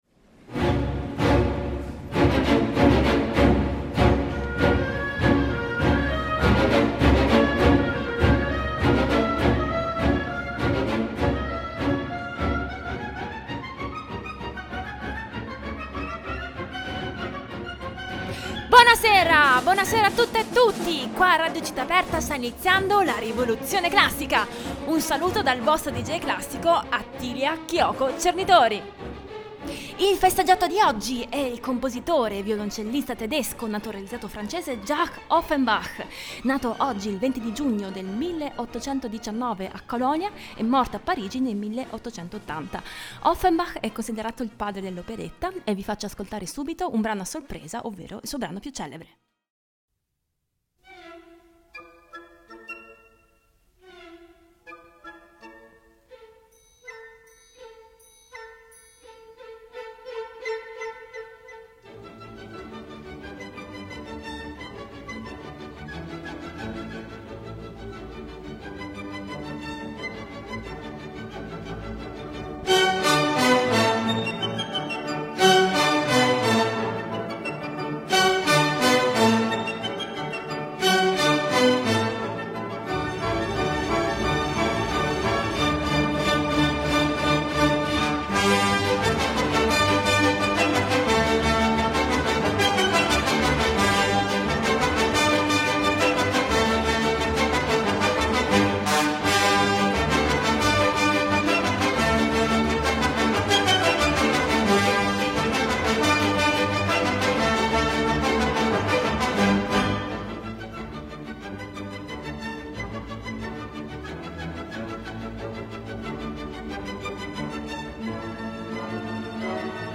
Ospite di questa puntata il violinista